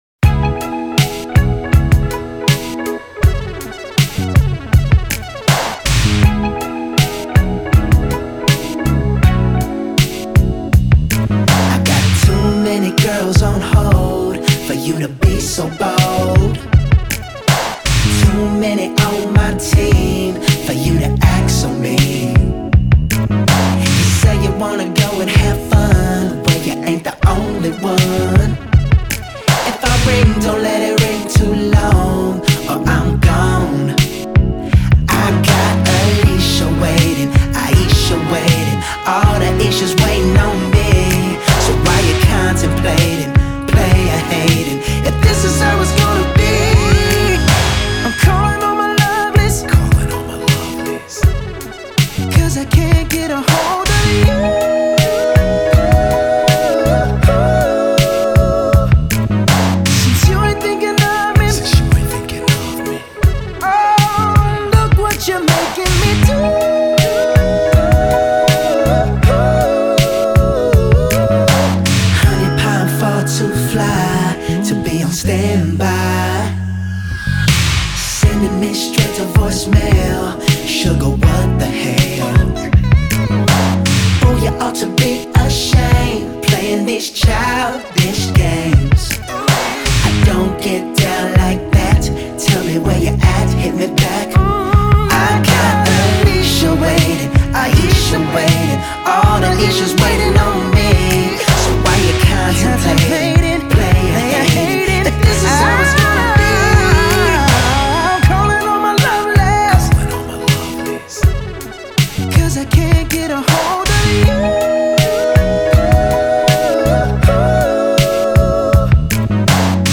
Pop/R&B